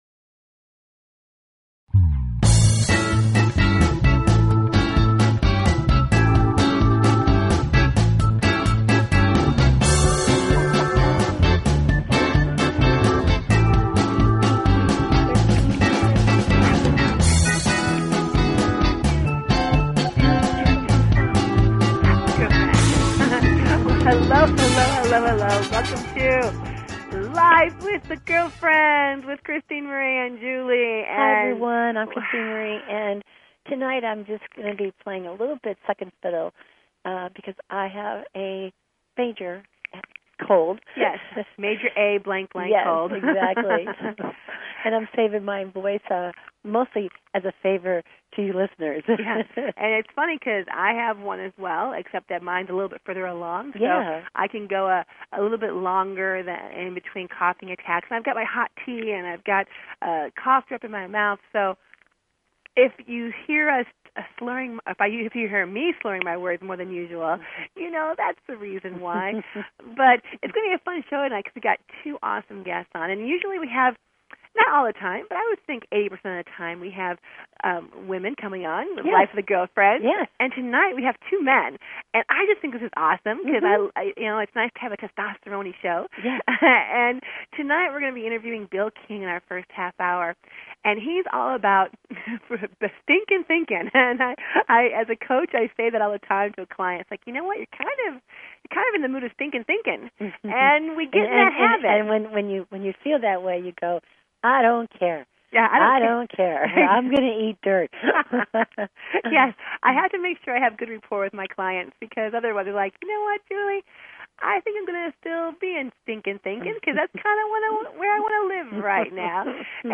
Talk Show Episode, Audio Podcast, Life_With_The_Girlfriends and Courtesy of BBS Radio on , show guests , about , categorized as